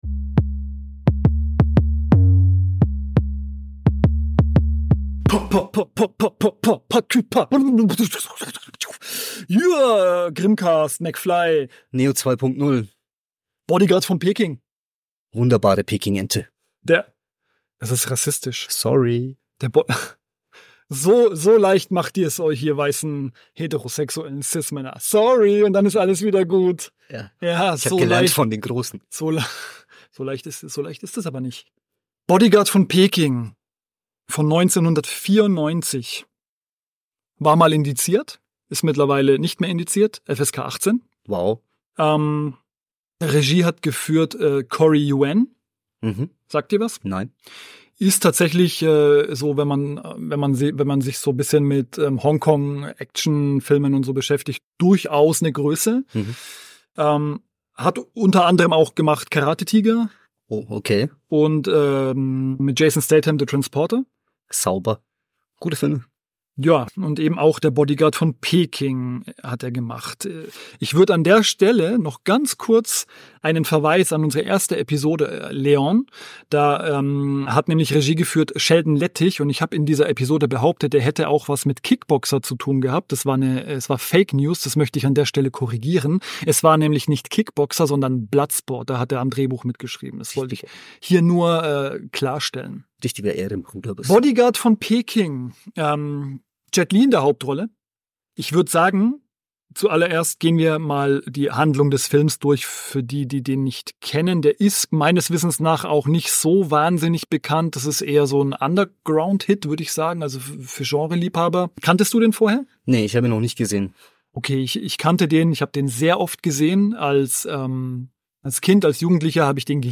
Bodyguard von Peking ist ein Martial Arts Kultklassiker. Selbstverständlich lassen es sich die politisch inkorrekten Brüder nicht nehmen über diesen Hong Kong Streifen mit Jet Li in der Hauptrolle zu sprechen.